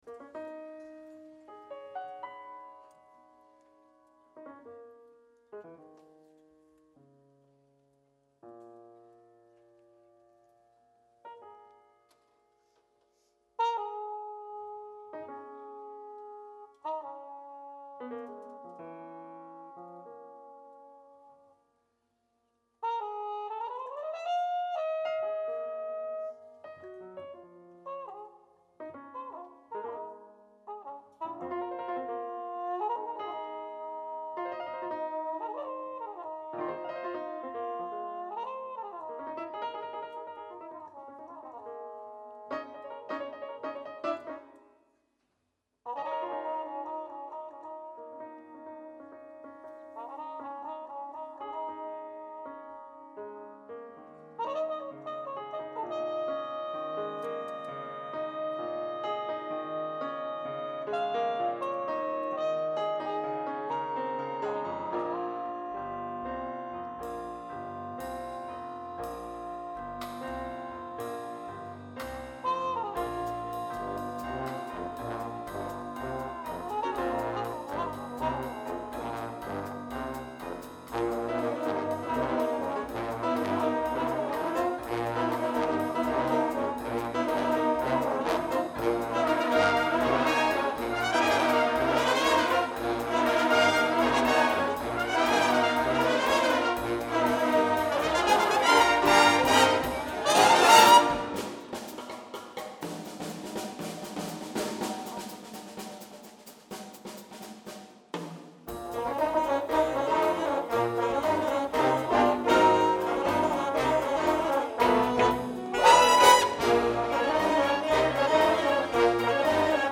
bateria
trompeta
piano
Contrabaix